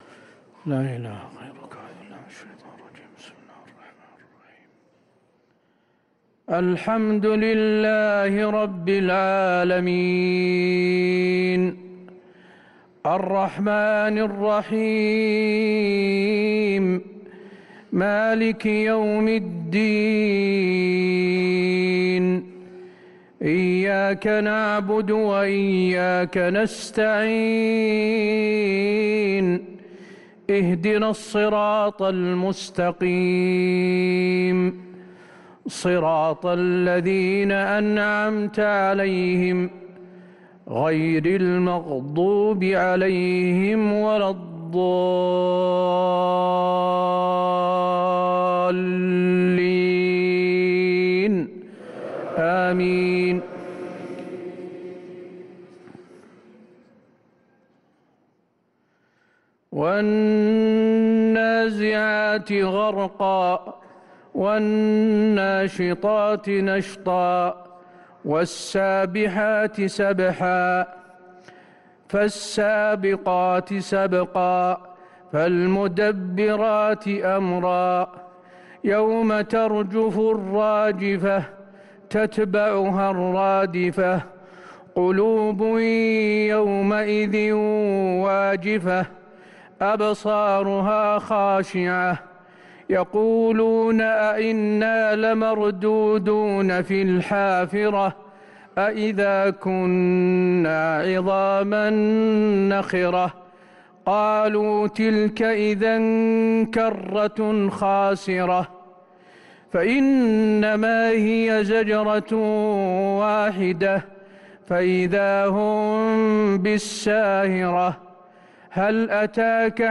صلاة العشاء للقارئ حسين آل الشيخ 6 ذو الحجة 1444 هـ
تِلَاوَات الْحَرَمَيْن .